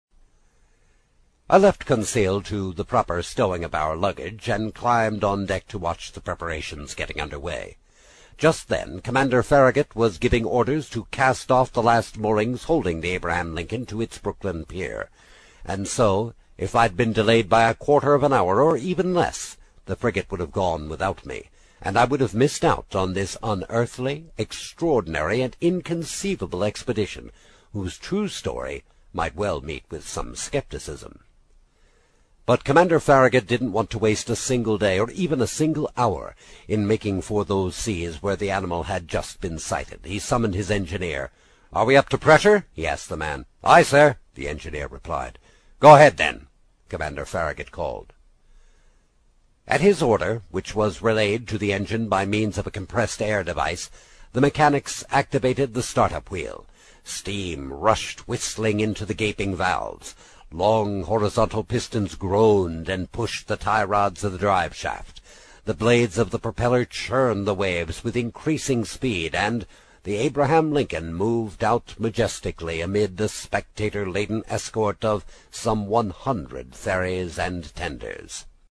在线英语听力室英语听书《海底两万里》第30期 第3章 随您先生的便(8)的听力文件下载,《海底两万里》中英双语有声读物附MP3下载